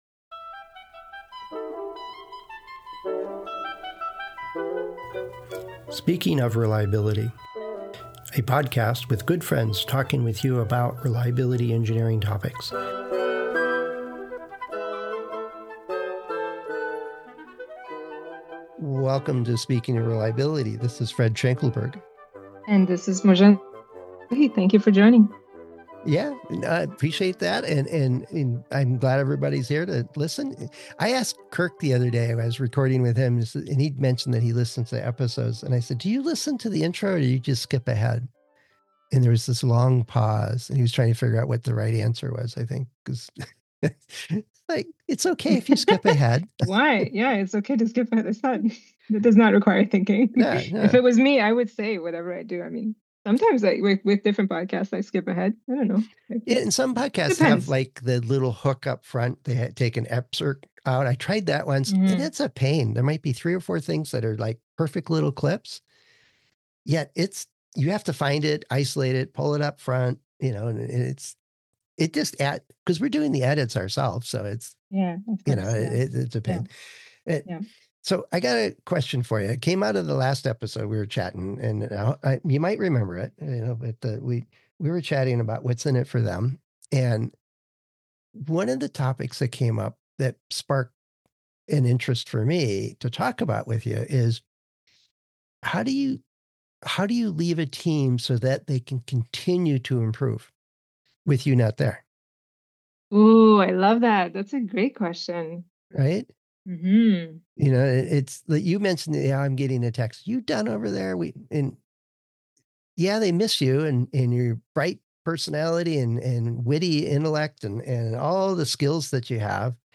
Speaking Of Reliability: Friends Discussing Reliability Engineering Topics